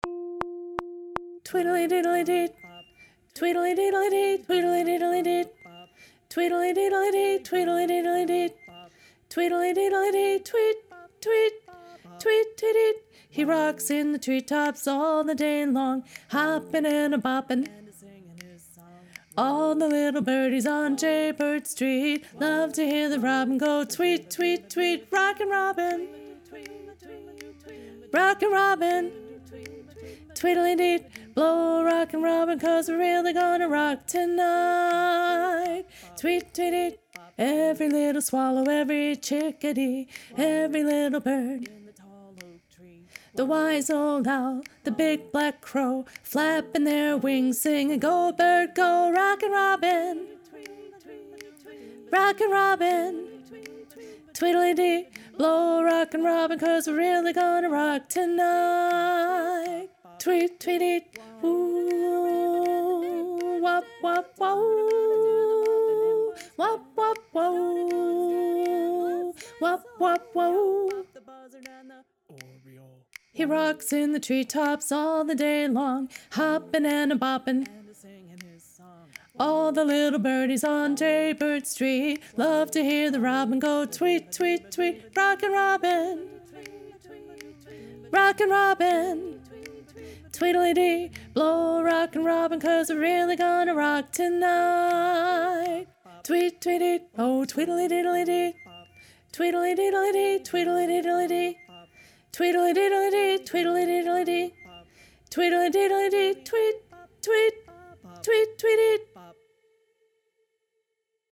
Soprano 2 (Lead)